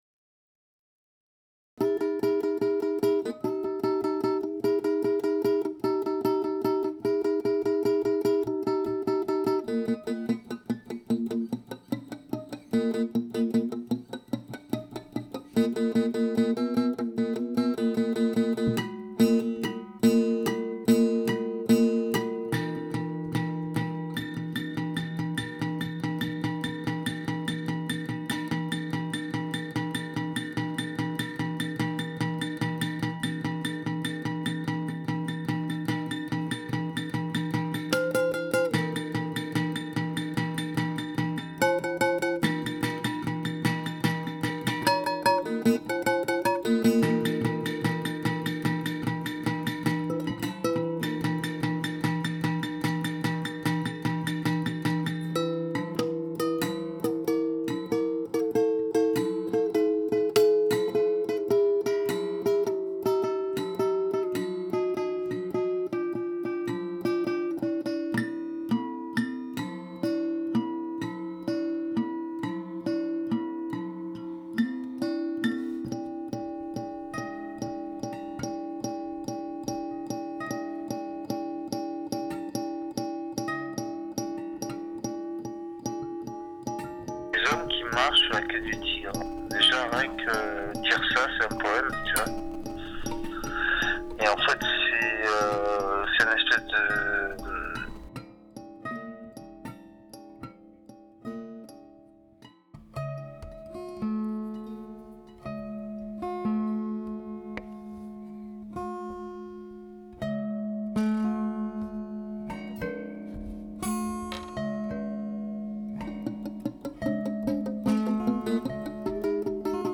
groupe de musique expérimentale
Groupe d’improvisation expérimentale marseillais.
Live, 2020